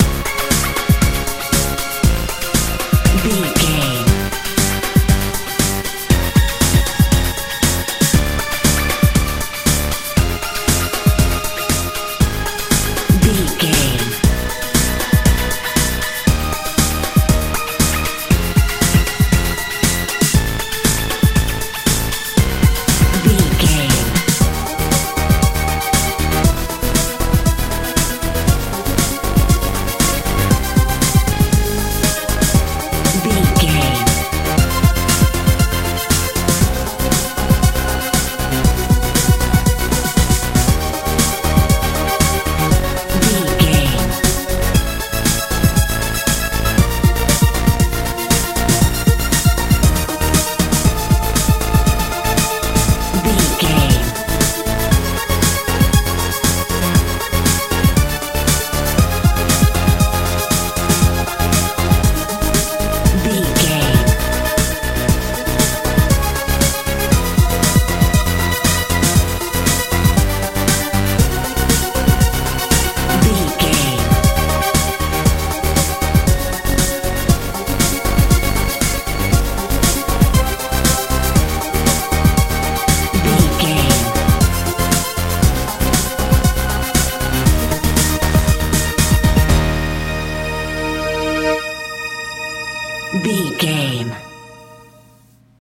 techno feel
Ionian/Major
C♯
confident
cool
bass guitar
drums
synthesiser
80s
90s